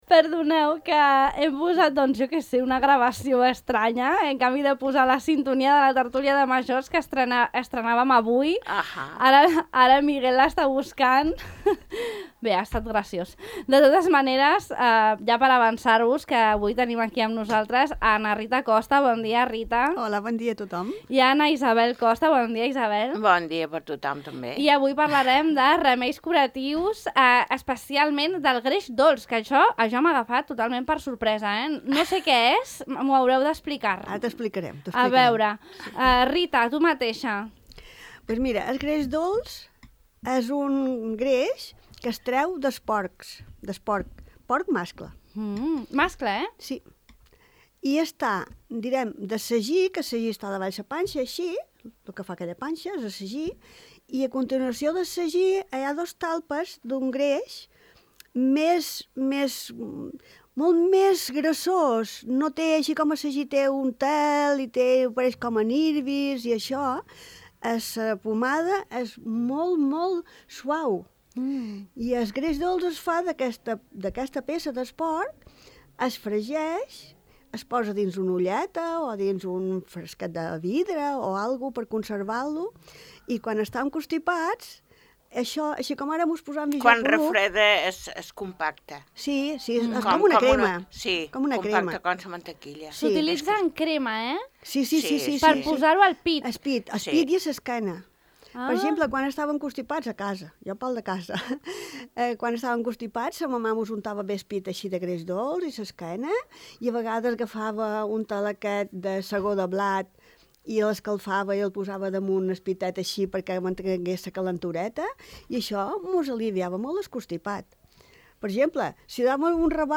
El 'greix dolç' com a remei curatiu, a la tertúlia de majors